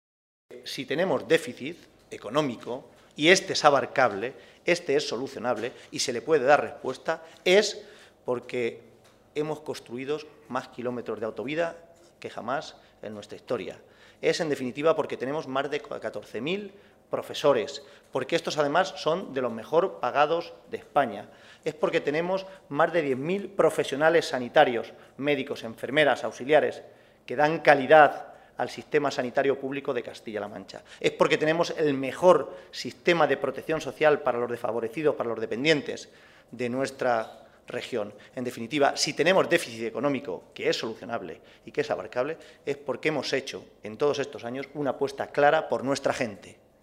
Caballero se pronunciaba así al comparecer ante los medios de comunicación para informar sobre el Comité Regional que los socialistas han celebrado esta tarde en Toledo.